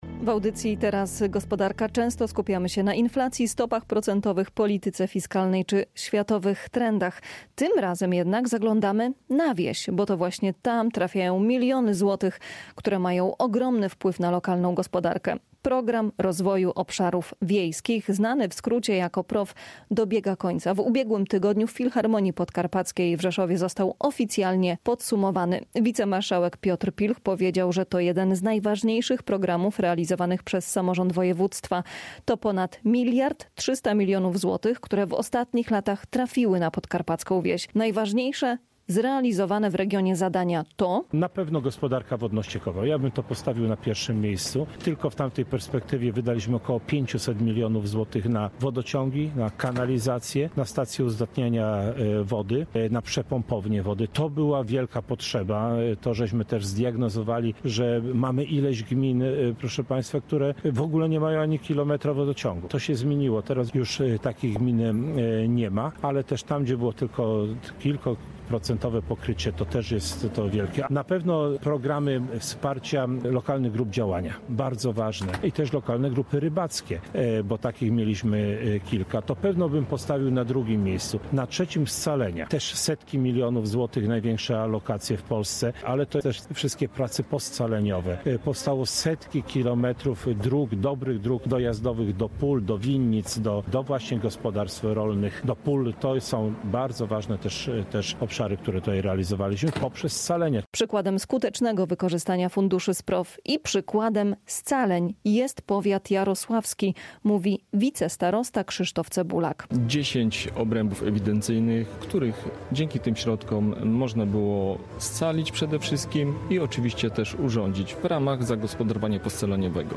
Podczas podsumowania w Filharmonii Podkarpackiej samorządowcy, przedstawiciele lokalnych grup działania i resortu rolnictwa opowiadali o tym, co udało się zrobić.